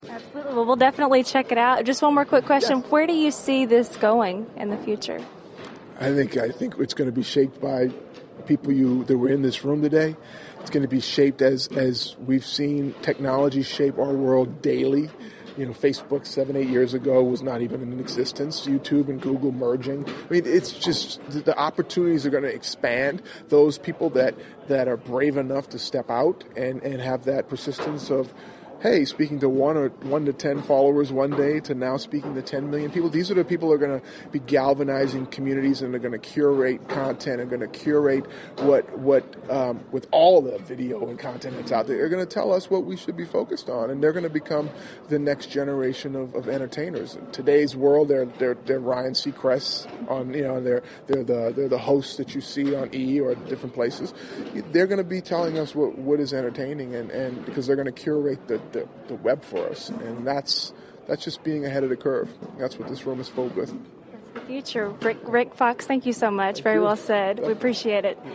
NBA star Rick Fox at Blogworld in LA on the future of entertainment thru the Internet.